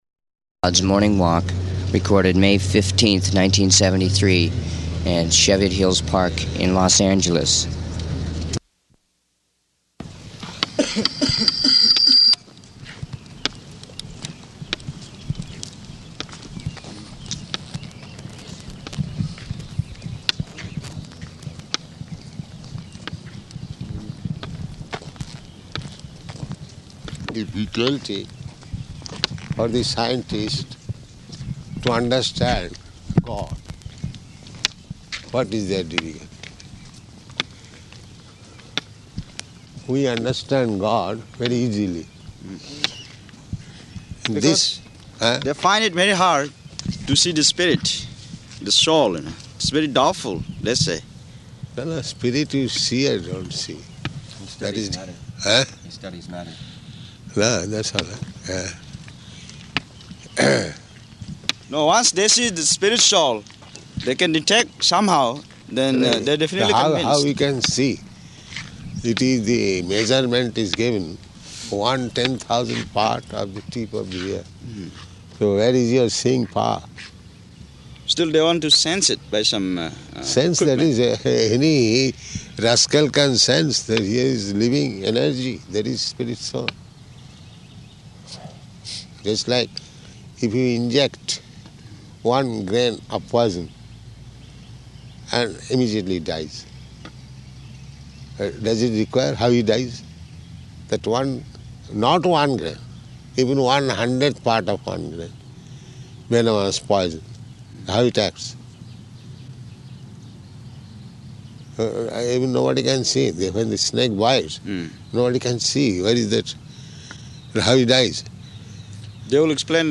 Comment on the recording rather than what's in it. Morning Walk at Cheviot Hills Golf Course